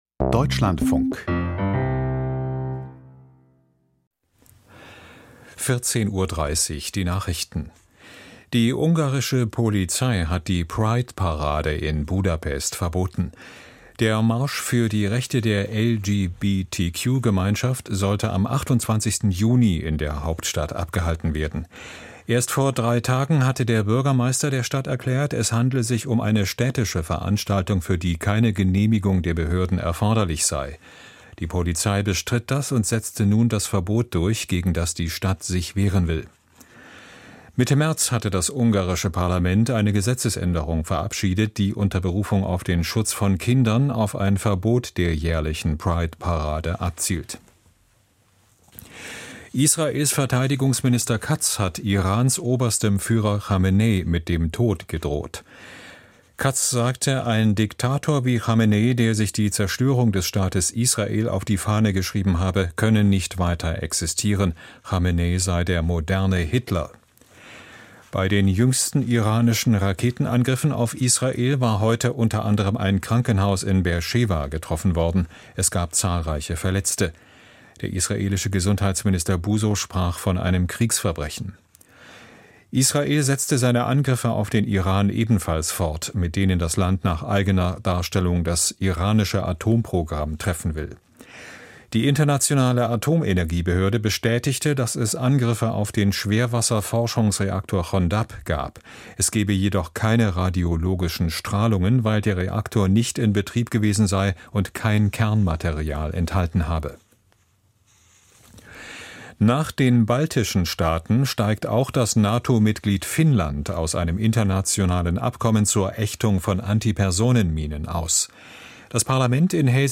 Die Nachrichten vom 19.06.2025, 14:30 Uhr